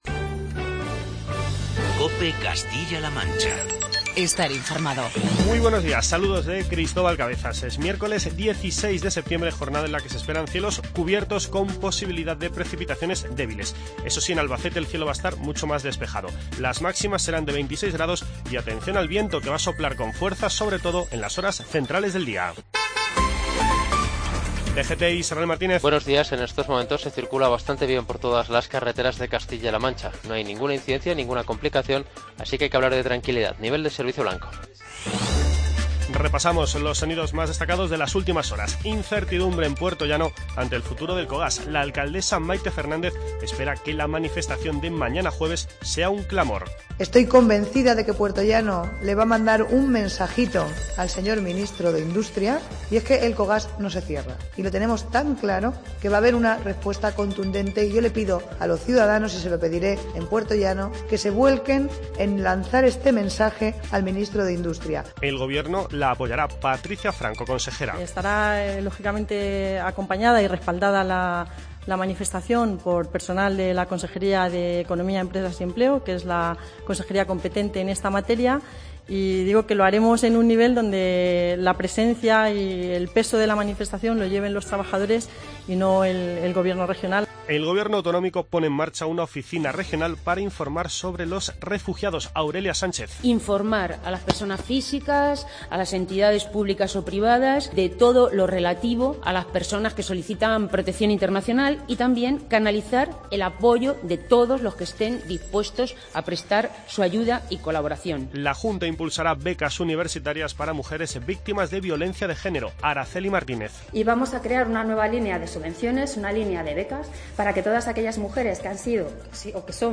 Informativo regional y provincial
Repasamos los sonidos más destacados de las últimas horas. Entre ellos, las palabras de Mayte Fernández, Patricia Franco y Aurelia Sánchez